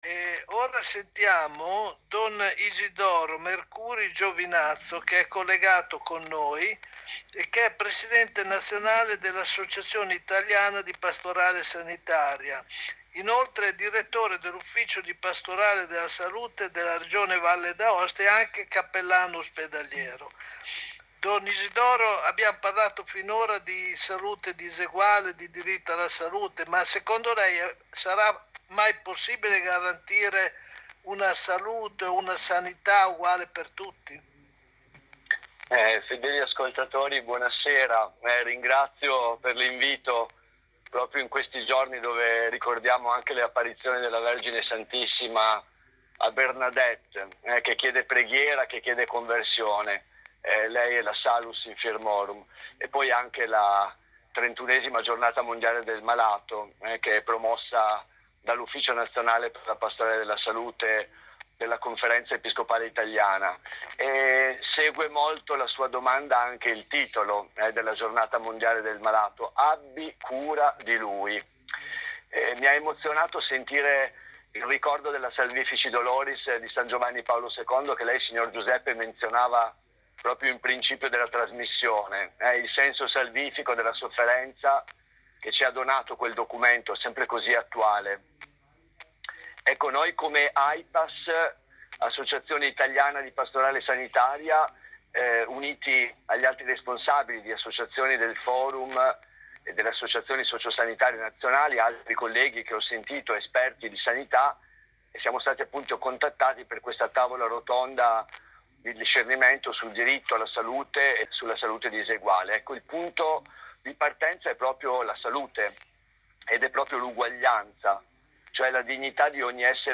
a margine del suo intervento alla Tavola rotonda radiofonica su 'Salute Diseguale' trasmessa il 12 febbraio scorso sulle frequenze di Radio Maria.